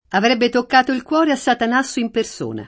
avr$bbe tokk#to il kU0re a SSatan#SSo im perS1na] (Verga) — sim. il cogn.